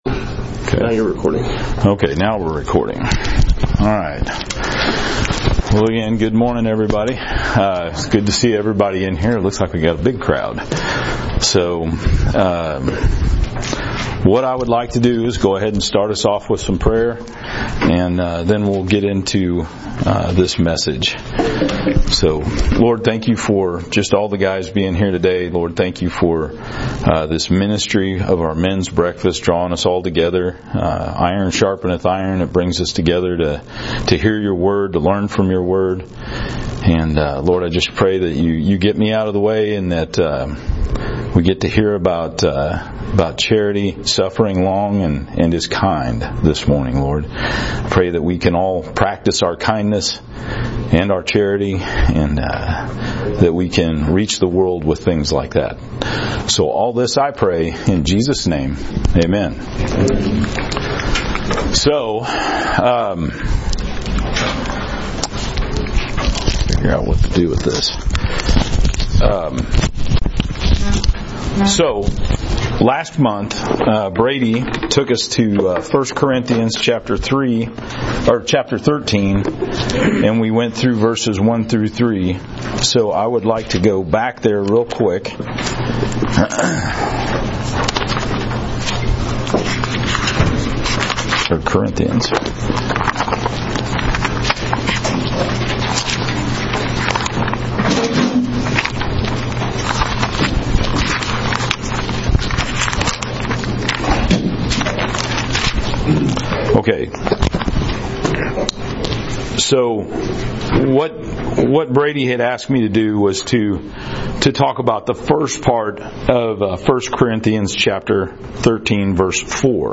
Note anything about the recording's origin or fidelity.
Men's Breakfast | Heartland Baptist Fellowship